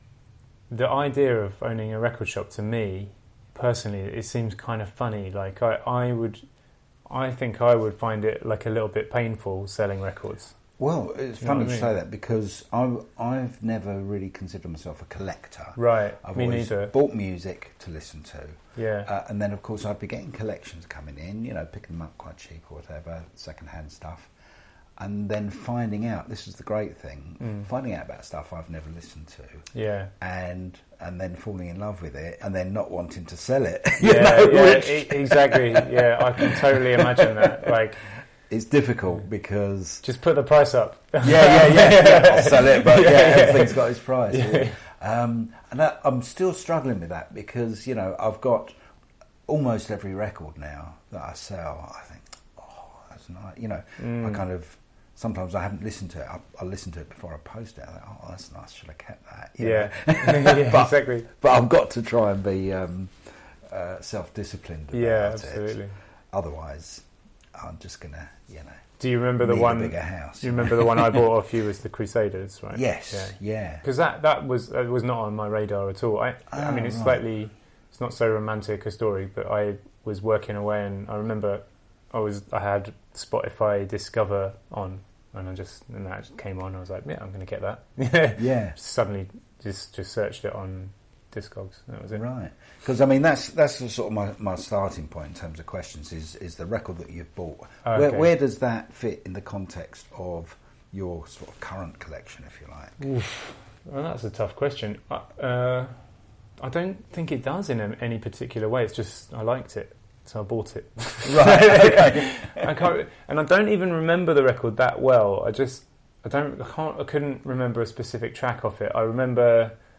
Note: these are just rough cuts at the moment, to give you an idea of the content.